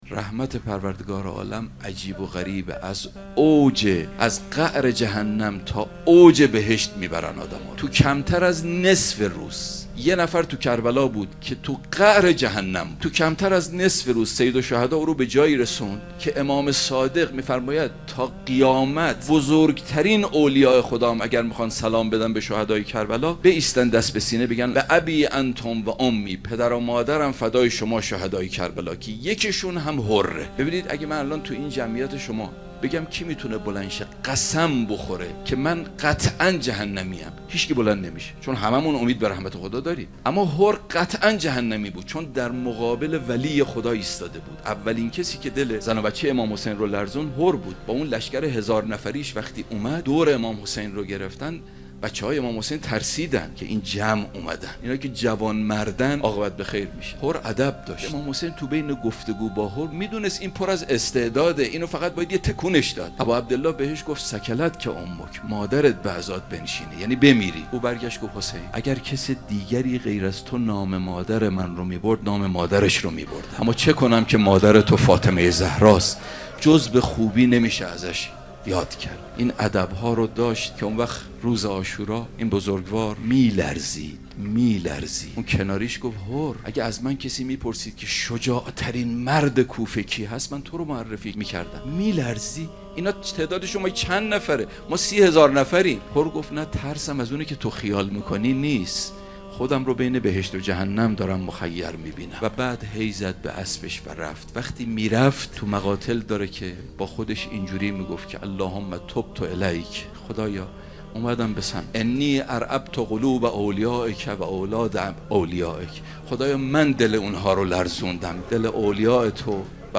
سخنرانی دهه اول محرم 1402